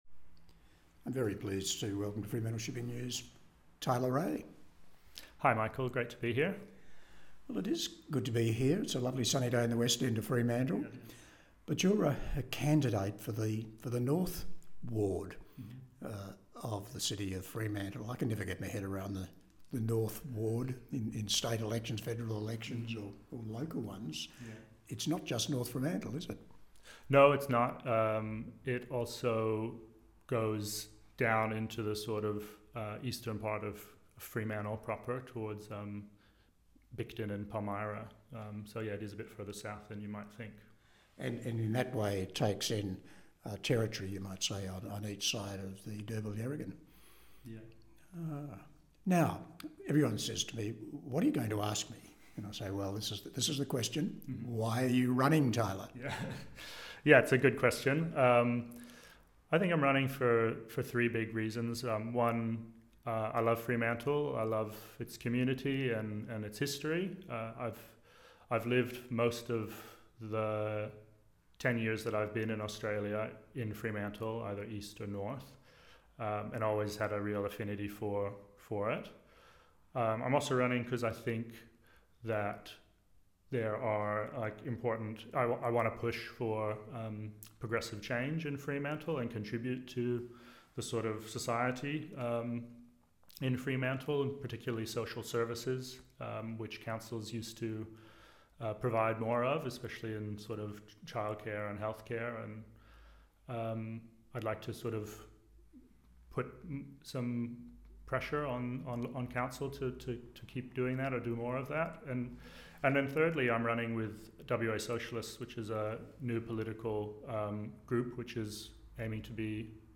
The North Ward Candidates Interviews
We have invited all candidates to be interviewed by our Editor in a getting-to -know-you podcast format explaining why they are running.